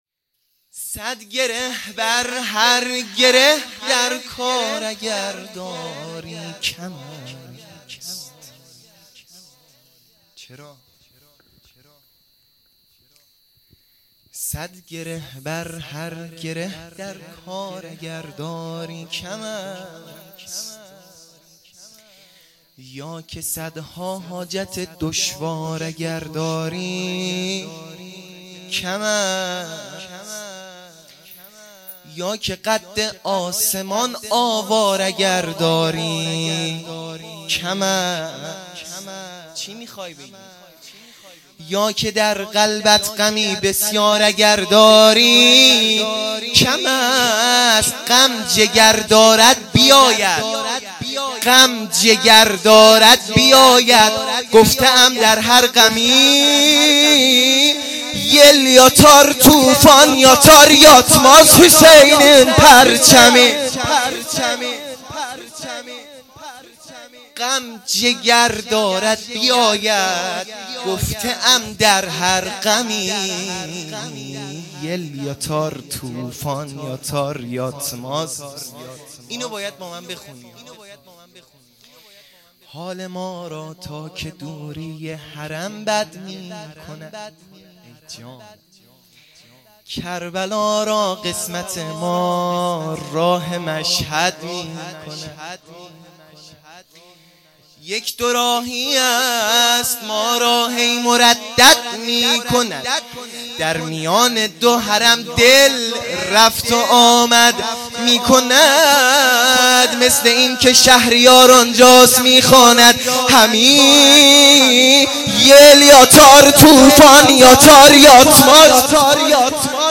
0 0 مدح خوانی یل یاتار طوفان یاتار
عیدانه سرداران کربلا | شب دوم